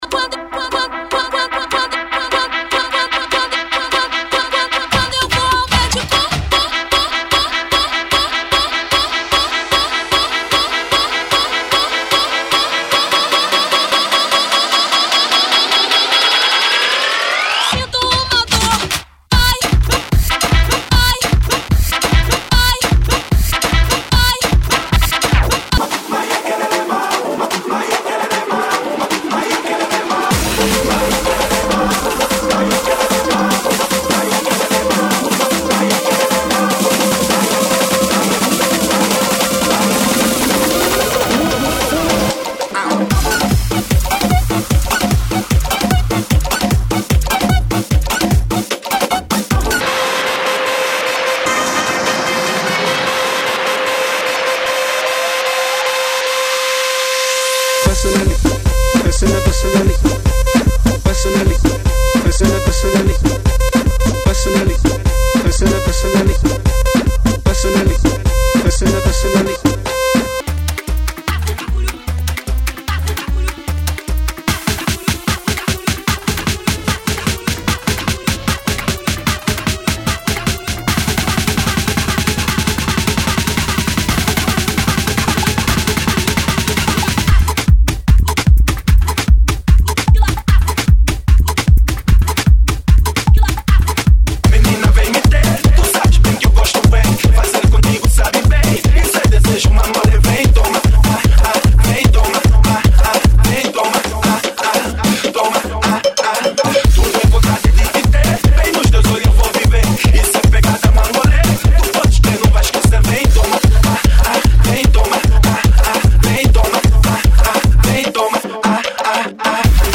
GENERO: BRASILEÑO – PORTUGUES – SAMBA
SAMBA BRASILIAN REMIX